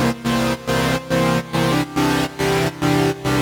Index of /musicradar/sidechained-samples/140bpm
GnS_Pad-MiscA1:4_140-C.wav